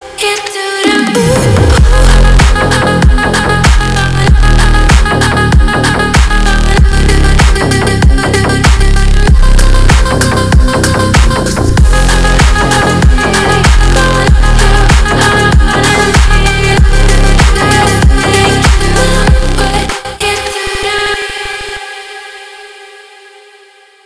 Качающий рингтон 2024